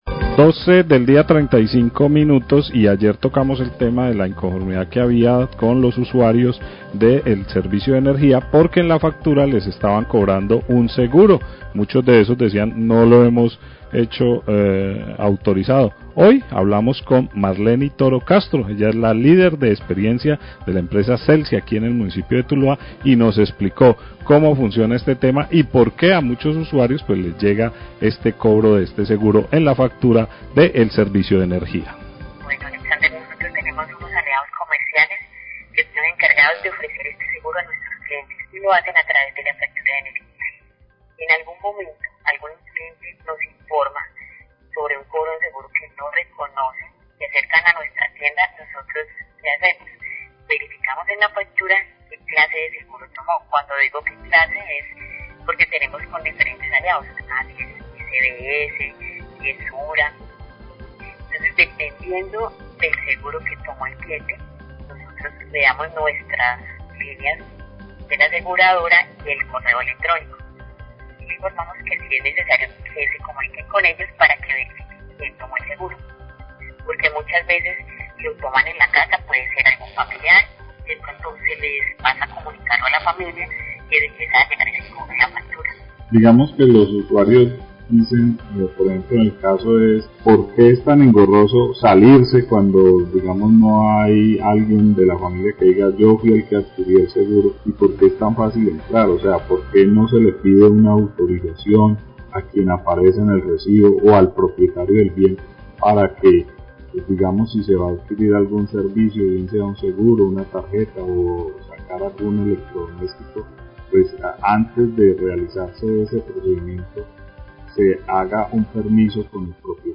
Radio
(dificultades de audio desde la fuente)